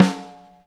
hit snare p.wav